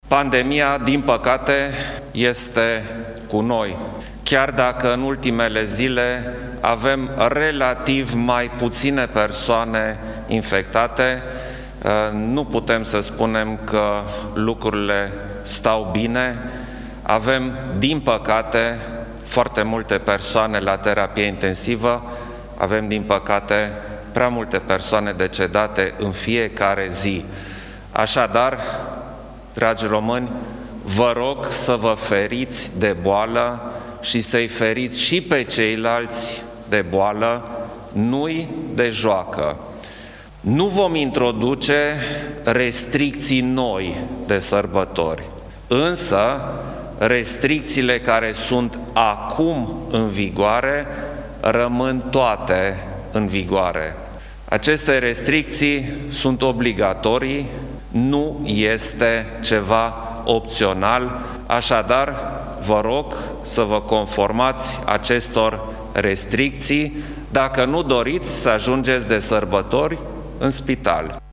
stiri-24-dec-Iohannis-restrictii.mp3